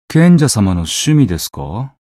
觉醒语音 这是贤者大人的趣味吗？